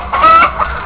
Hen 1
HEN_1.wav